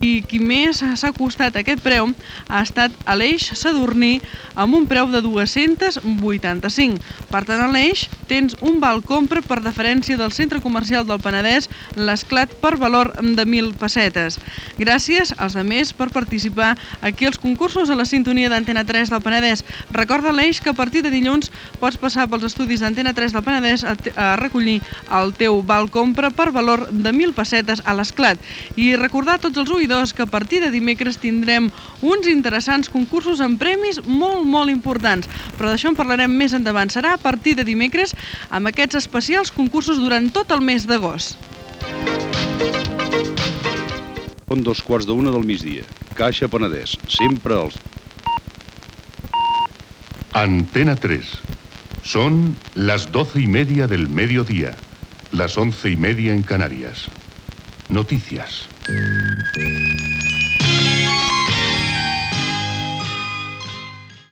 Resultat del concurs L'Esclat i careta notícies Antena 3.